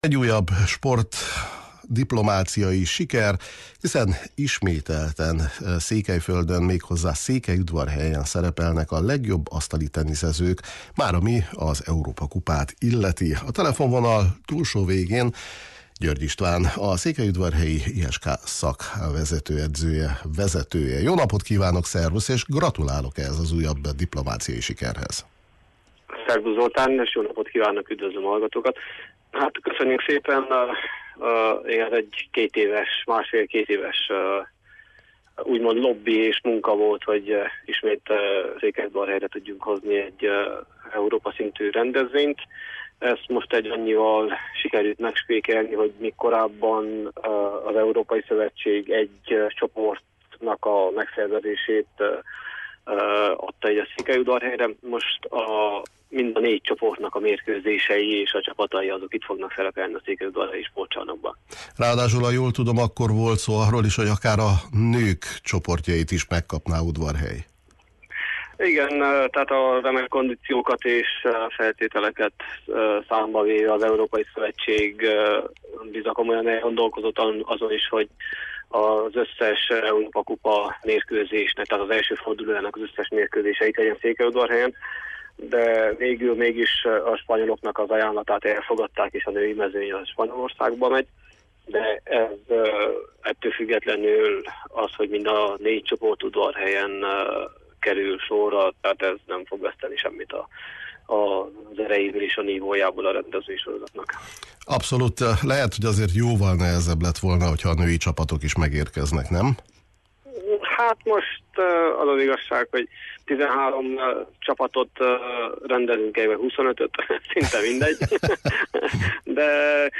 a Kispad sportműsorunkban